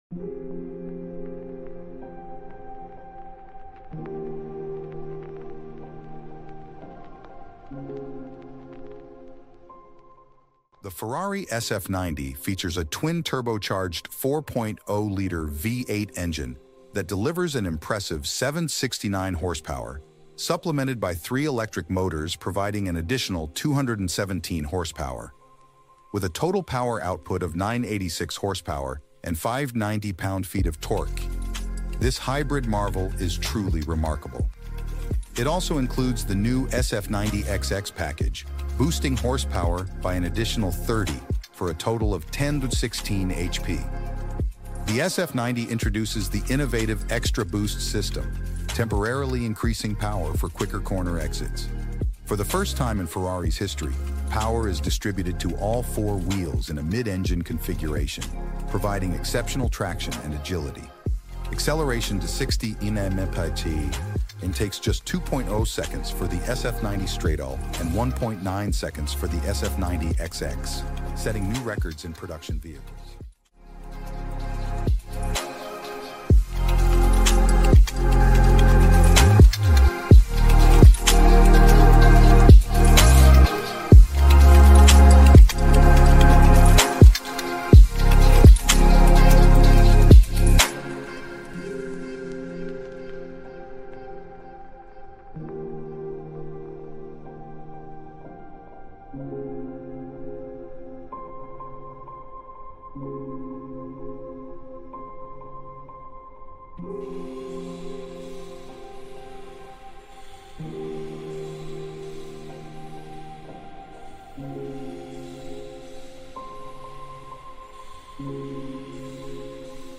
2024 Ferrari SF90 Stradale V8 sound effects free download